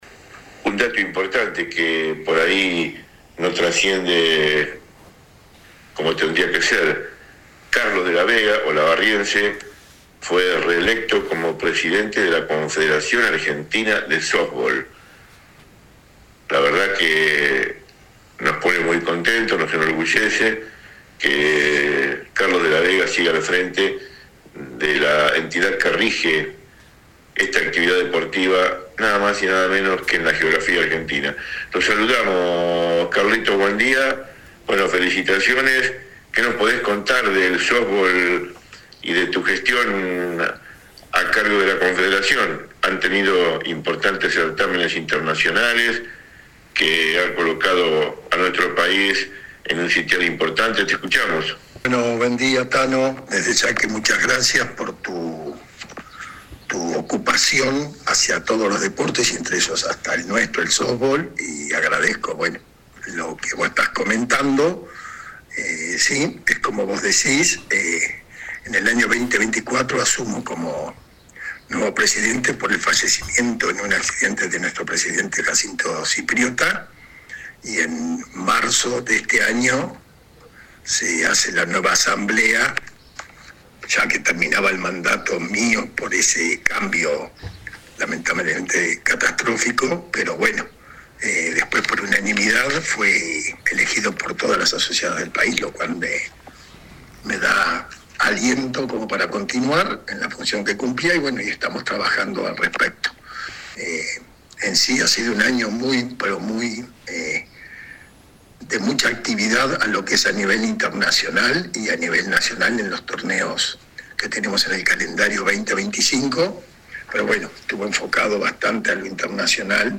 AUDIO DE LA ENTREVISTA ( en tres bloques )